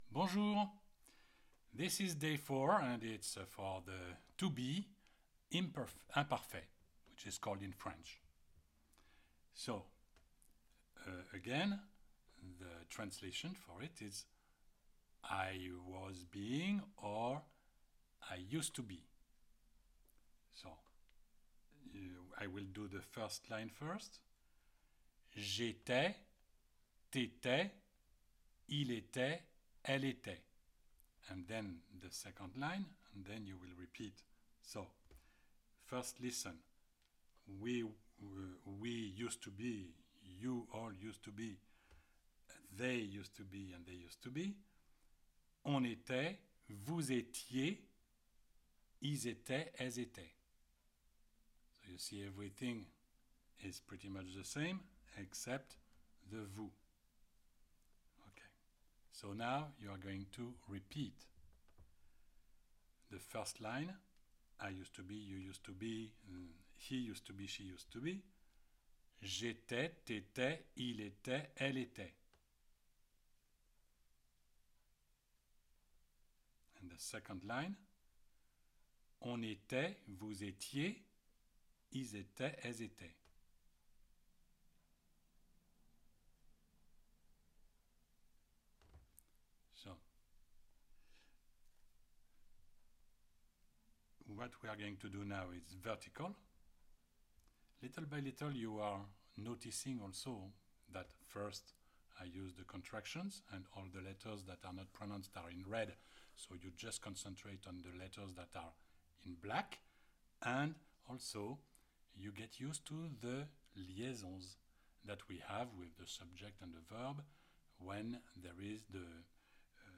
THAT IS ALSO WHY I SIMPLY ASK YOU TO IMITATE ME OVER AND OVER USING A LANGUAGE THAT IS CONTRACTED SO THAT YOU WILL FEEL EMPOWERED WHEN THE TIME COMES FOR YOU TO COMMUNICATE WITH THE FRENCH.